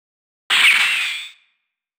～【効果音】～
ばきゅーん